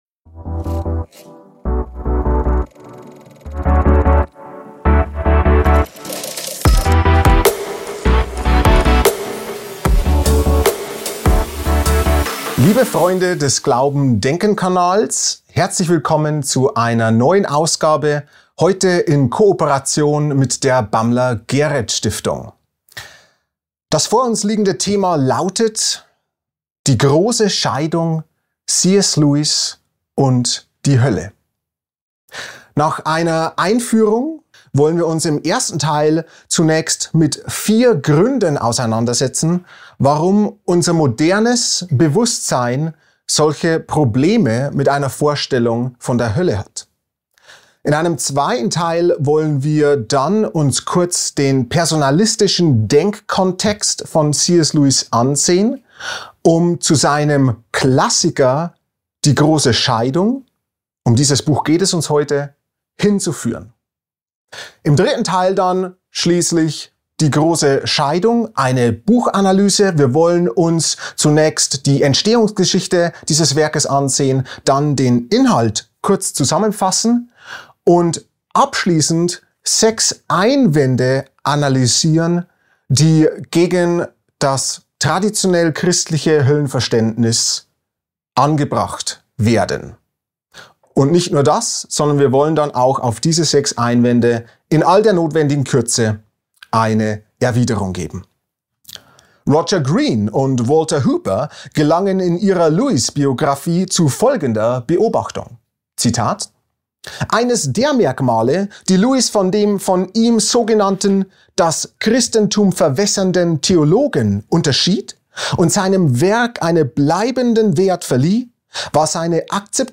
Darin stellt er sich einem der modernen Haupteinwände gegen den christlichen Glauben: Wie kann ein guter und liebender Gott Menschen in der Hölle leiden lassen? Dieser Vortrag bietet nicht nur eine Einführung in Lewis' Meisterwerk, sondern analysiert darüber hinaus Gründe, warum unser modernes Bewusstsein Probleme mit der Hölle hat. Außerdem wird am Ende auf die sechs häufigsten Einwände gegen das traditionell christliche Höllenverständnis eingegangen.